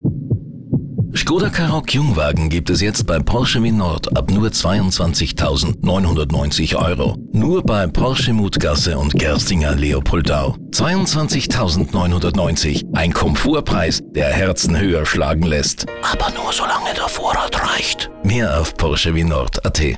Radio Spot